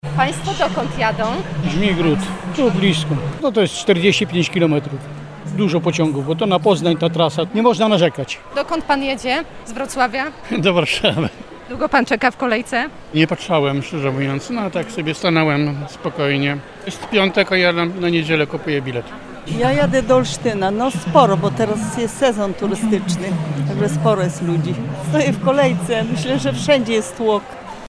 Jak wygląda sytuacja na Dworcu we Wrocławiu? Sprawdziliśmy z mikrofonem.
01-pasazerowie-pkp.mp3